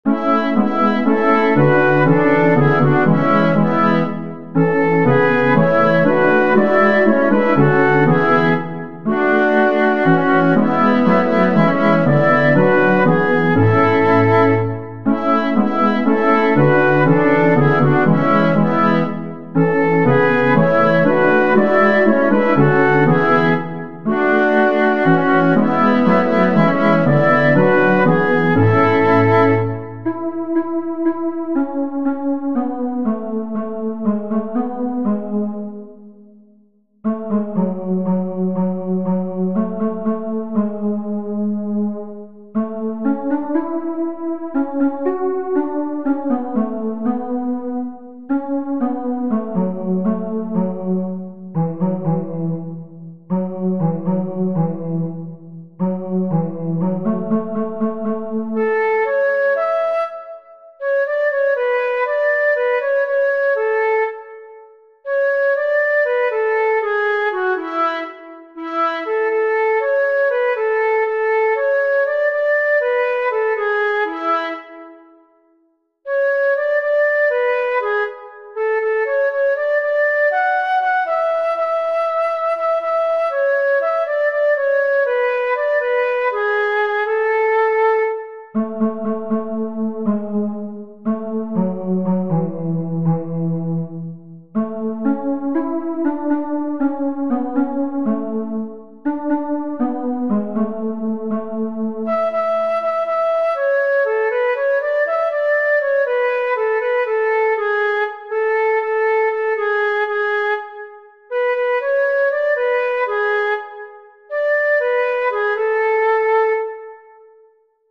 Makundi Nyimbo: Zaburi